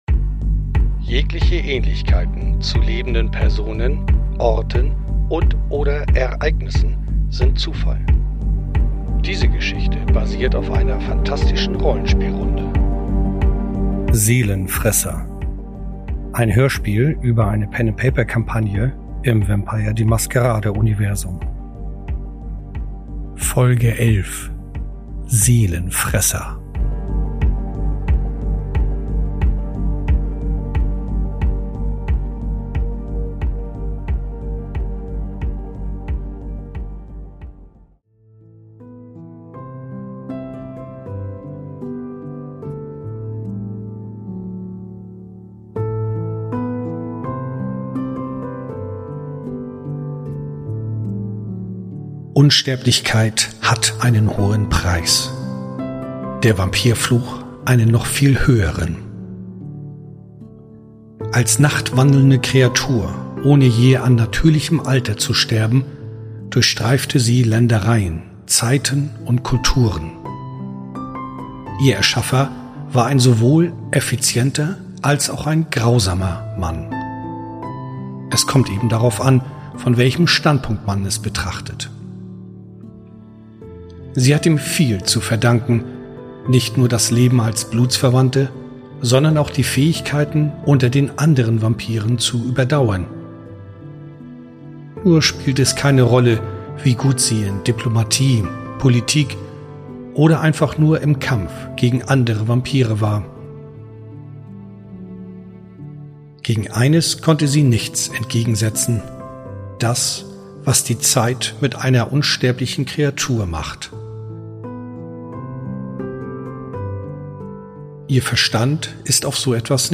Das sie dabei sich in das völlige Chaos stürzen, hat keiner in diesem Ausmaße erahnt. Die Finale Folge von dem Hörspiel "Seelenfresser" findet ihren Abschluss in einem epischen Ende.